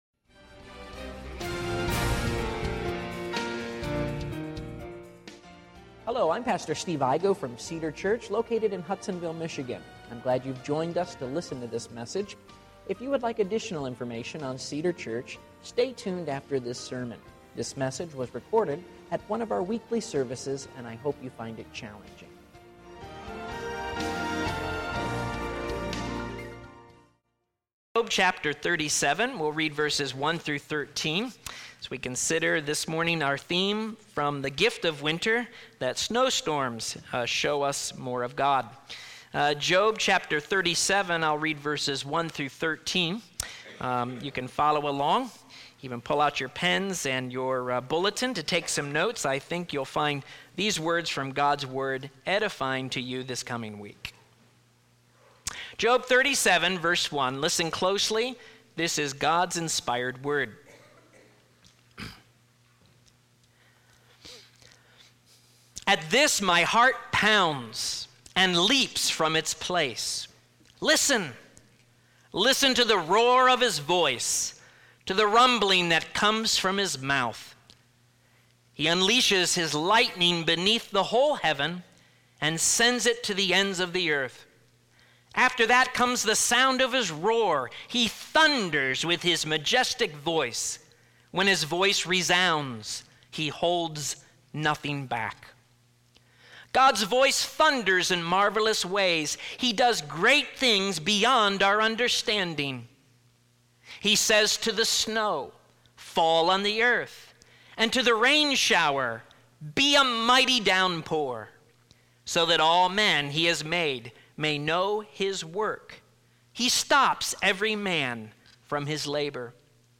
Sermons | Cedar Church